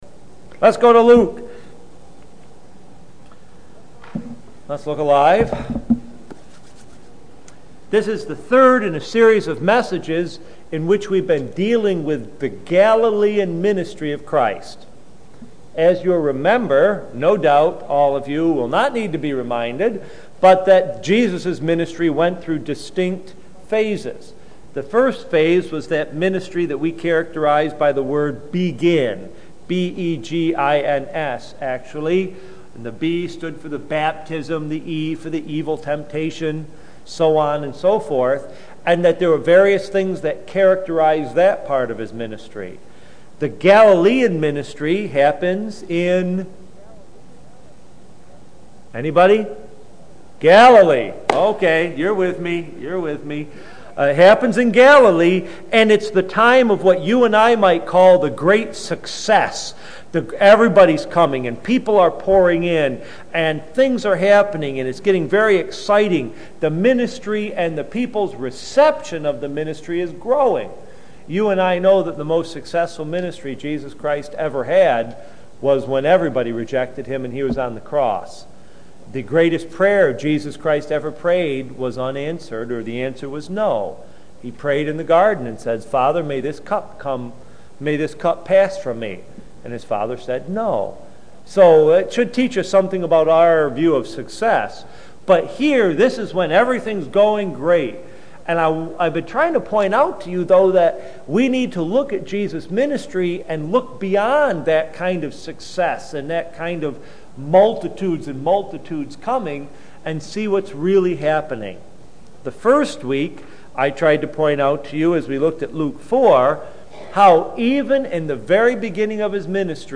Galilean Ministry Passage: Luke 5:17-26 Church: Brooktondale AM %todo_render% « What God Expects in Leaders Discerning